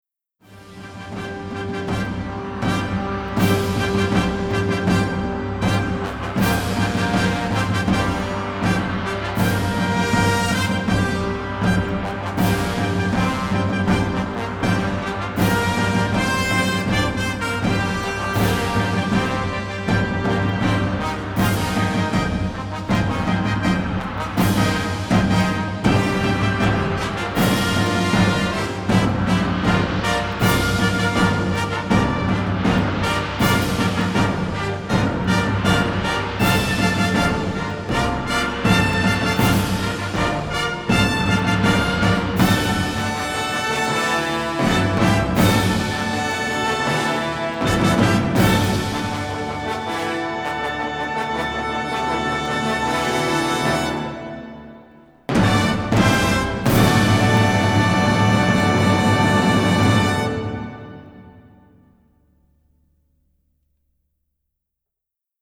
records and mixes at AIR Studios in London